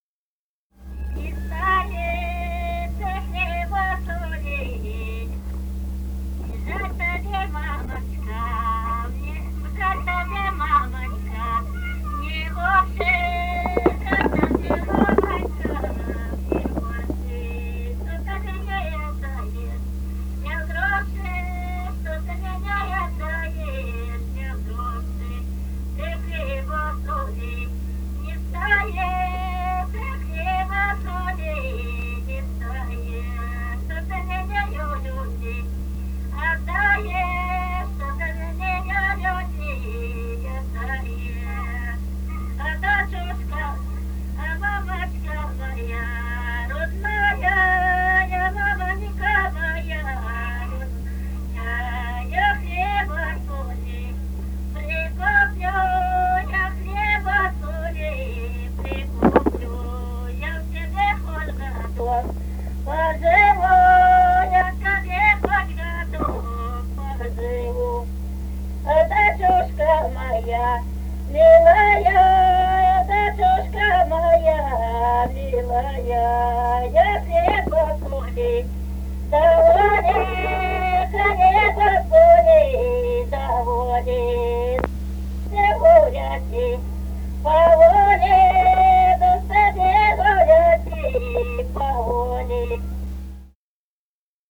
Русские народные песни Красноярского края.